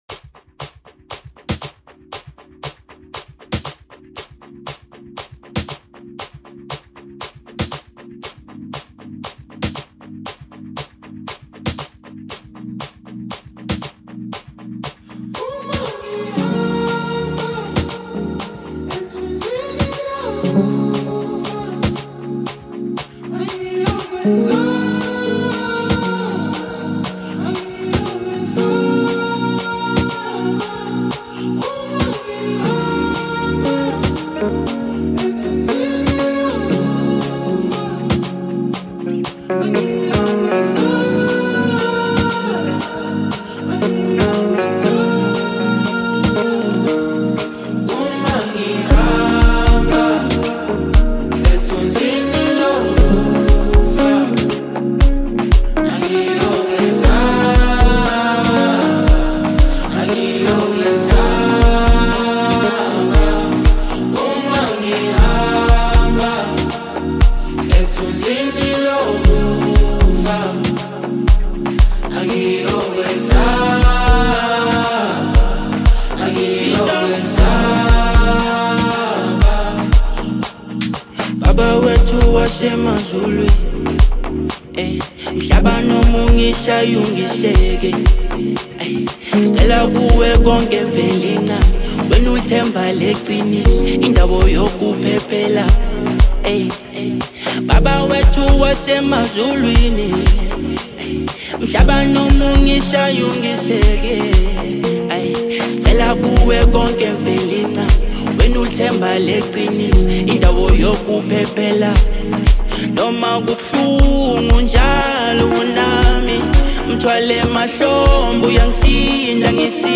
soul-stirring song